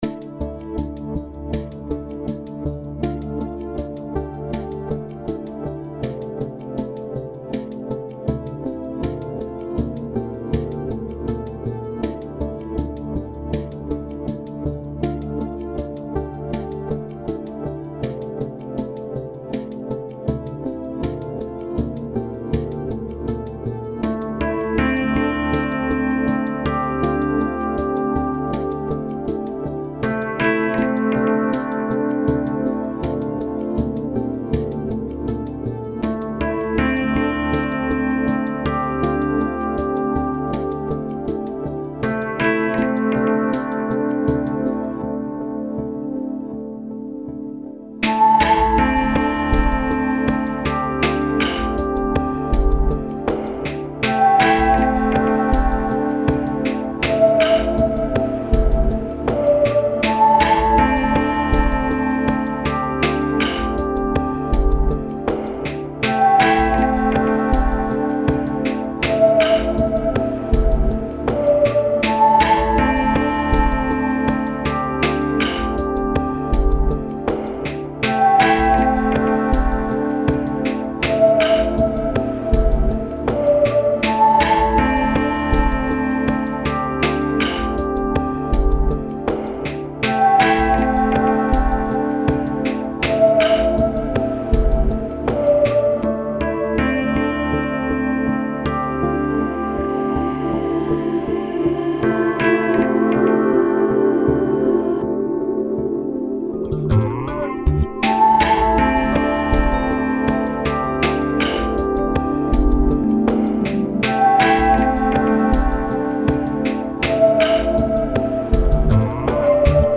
Genre Ambient